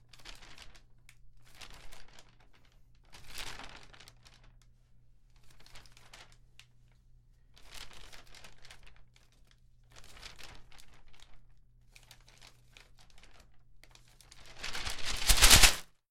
描述：弗利练习张口
声道立体声